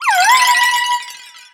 Cri de Créhelf dans Pokémon X et Y.